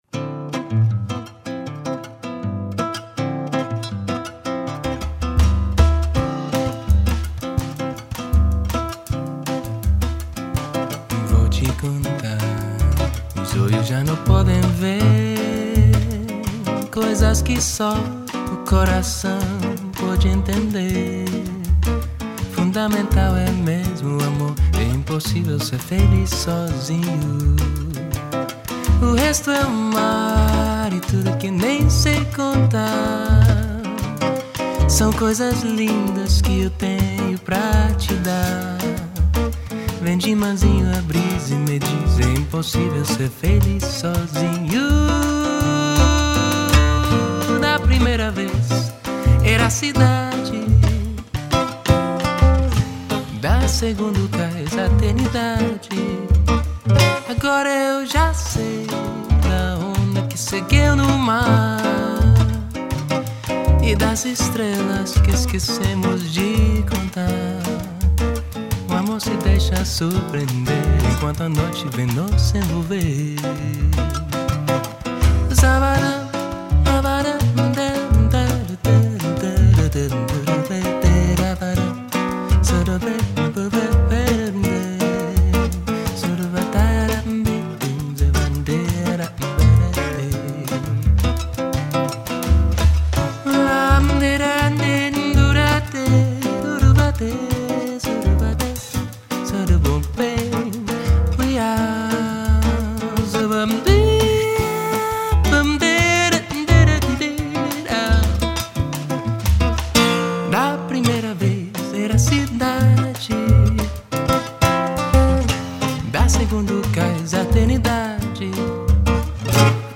Solo Brazilian CD released December, 2002.
Some tunes with just guitar and vocals, others
with supporting instrumentation: percussion,
fretless bass, piano...